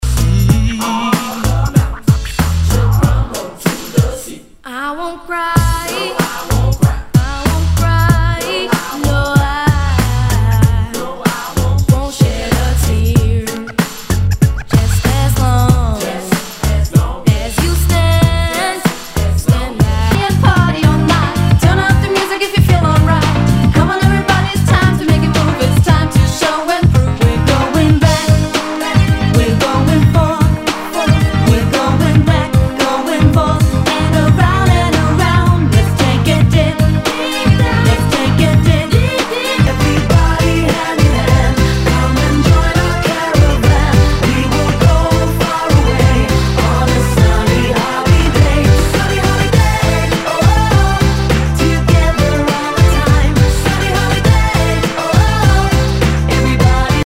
HIPHOP/R&B
ナイス！ユーロ・ポップR&B！